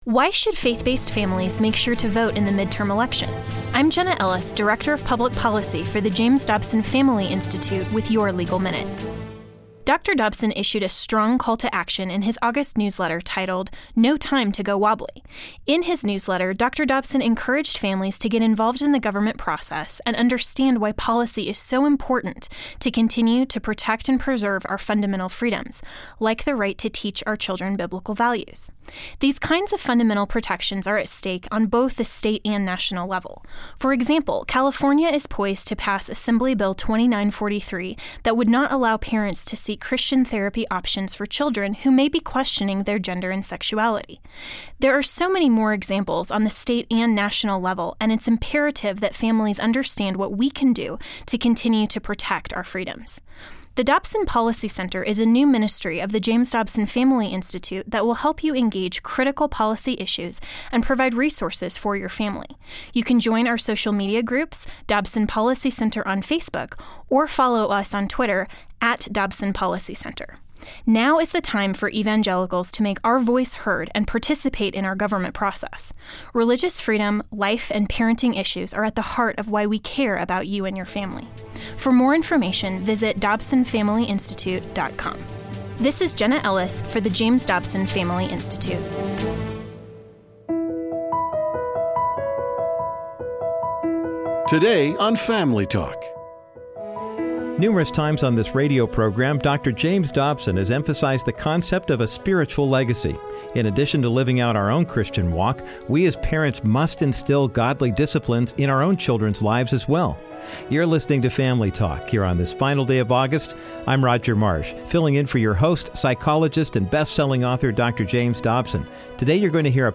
It is up to Godly parents to intentionally pass on the baton of faith to their children. Dr. Bruce Wilkinson delivers a passionate message on the importance of a spiritual legacy. He urges parents to not allow compromise or apathy to seep into their Christian walk.